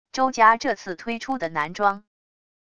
周家这次推出的男装wav音频